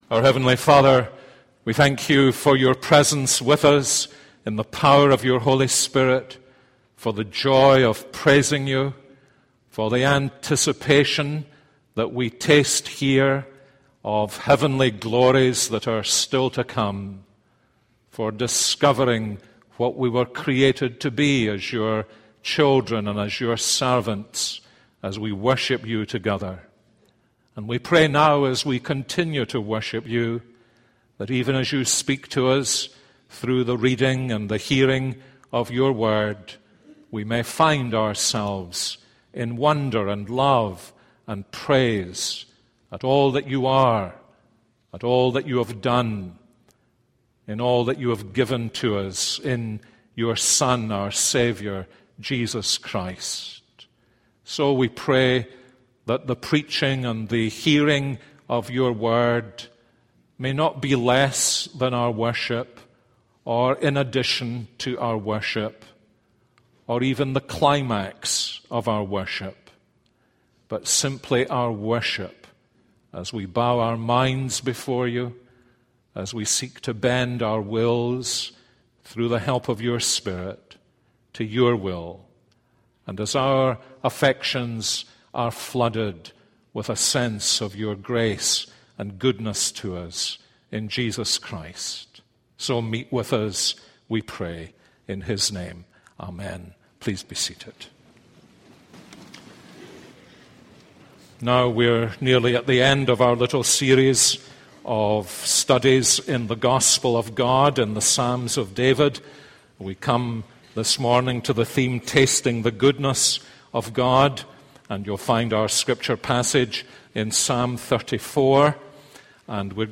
This is a sermon on Psalm 34:1-10.